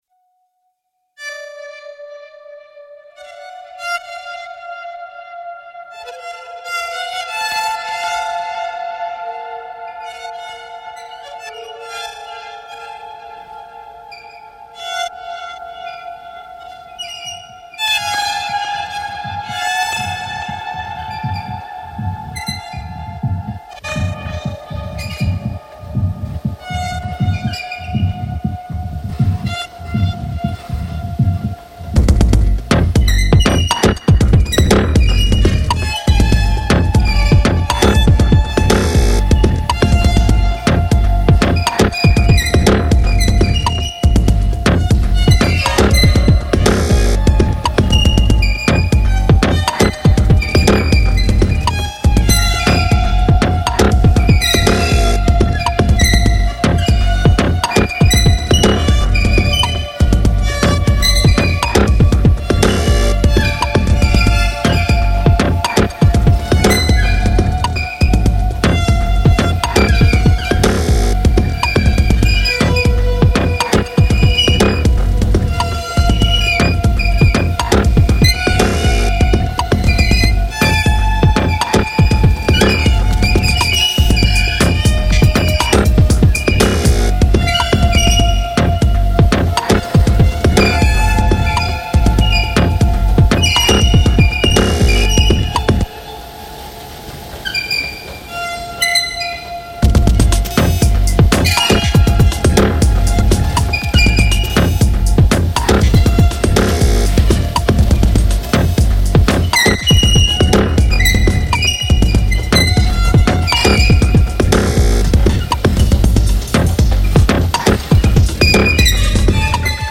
appropriate both to the escalators running in the abandoned shopping centre and the fact that the squeaks sound like sneakers on the gym floor!" Escalators in Oxford reimagined